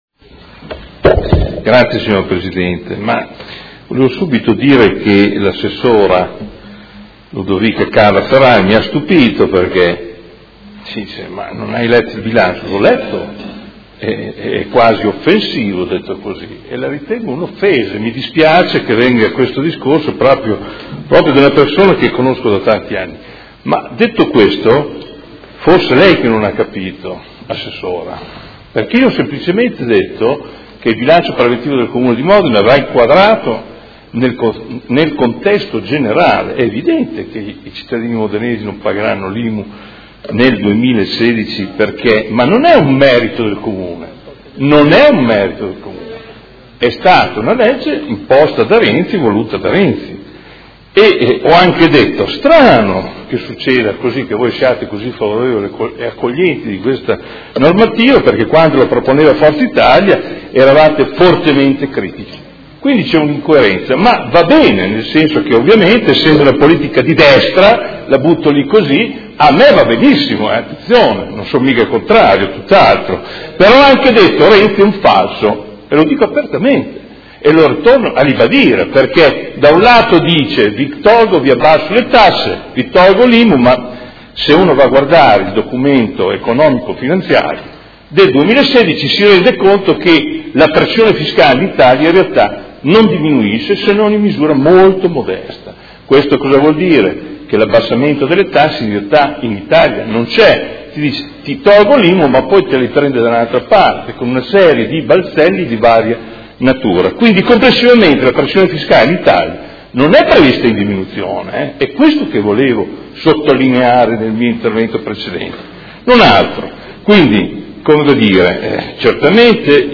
Seduta del 25 febbraio. Approvazione Bilancio: Dichiarazioni di voto